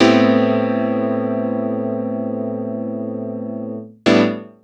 GoodChords-44S.wav